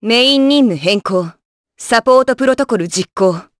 Hilda-Vox_Skill3_jp.wav